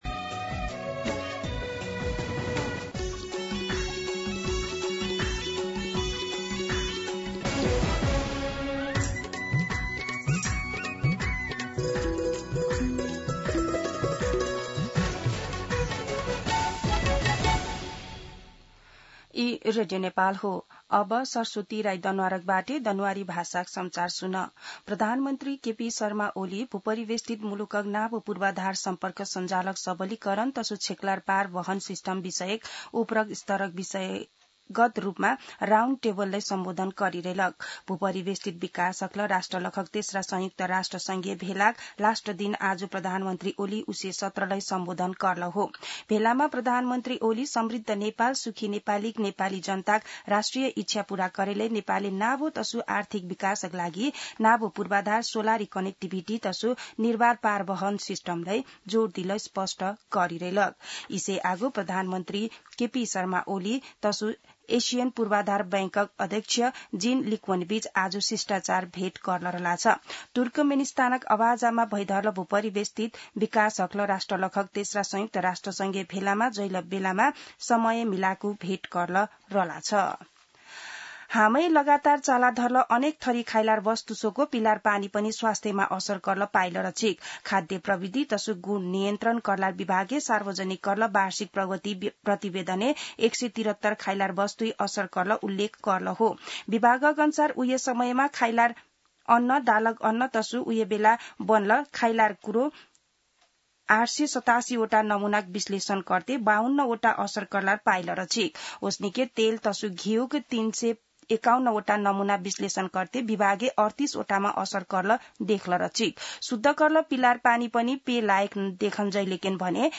दनुवार भाषामा समाचार : २२ साउन , २०८२
Danuwar-News-2.mp3